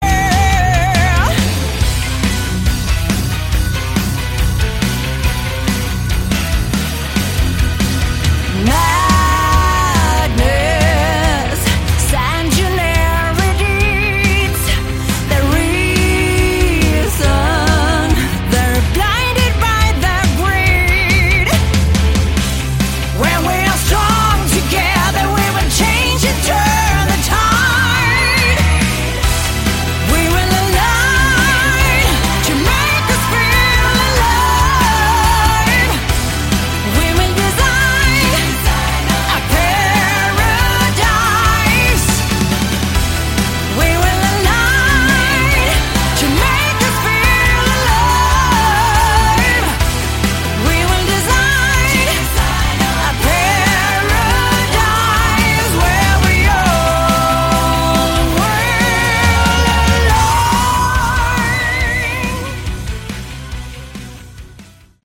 Category: Melodic Rock
keyboard/Hammond organ